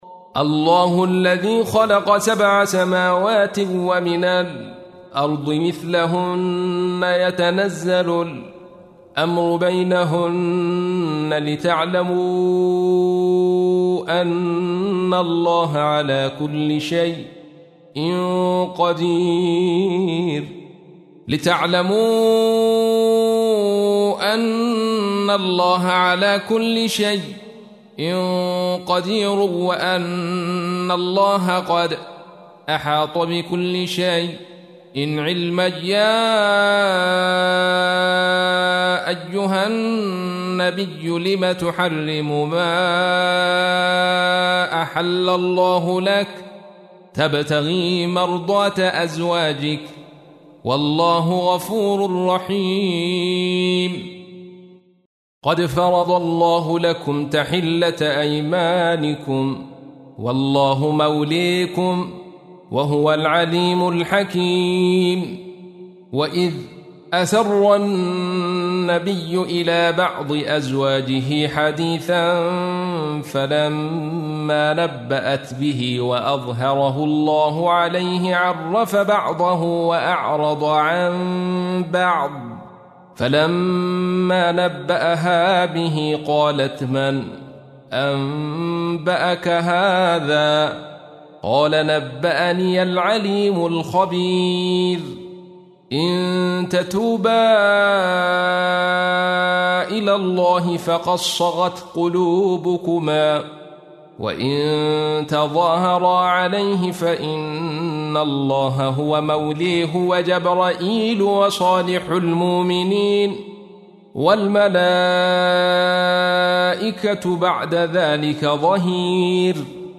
تحميل : 66. سورة التحريم / القارئ عبد الرشيد صوفي / القرآن الكريم / موقع يا حسين